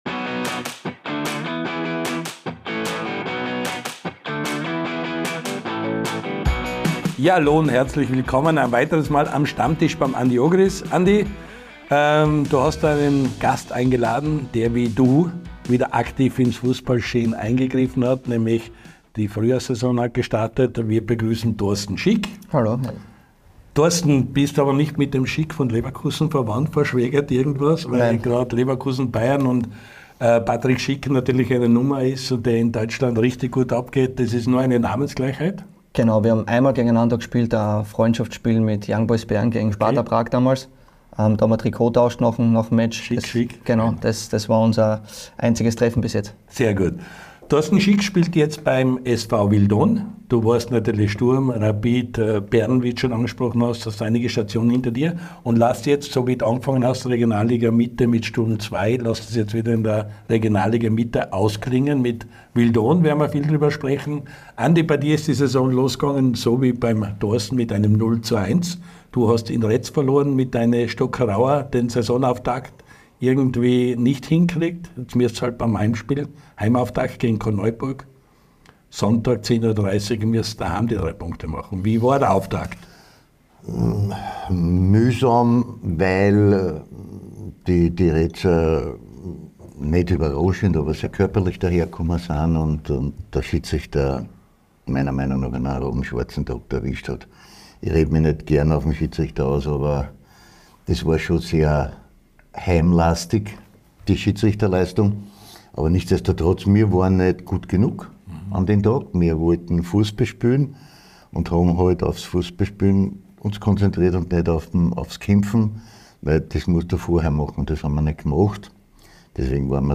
Der LAOLA1-Kult-Talk von und mit Andy Ogris!